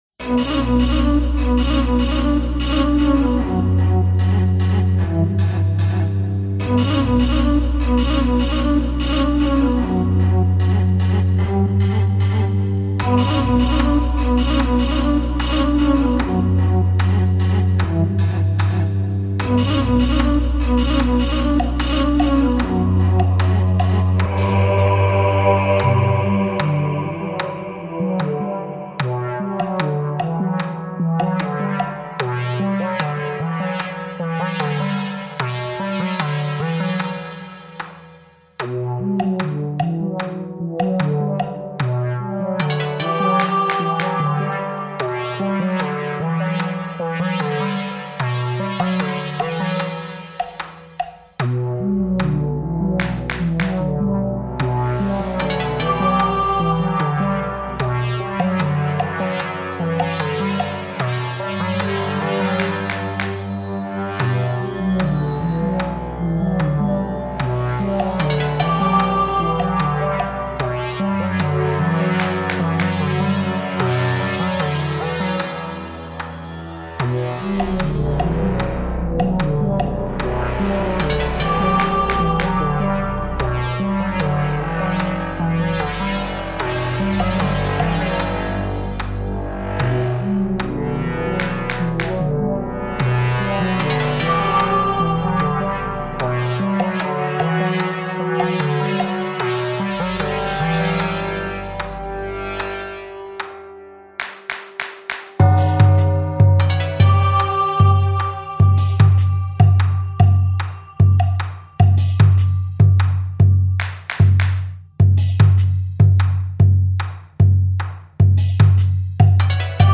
Mantra tombée d’Asie.
Délicieuse plongée dans les palais d’Orient.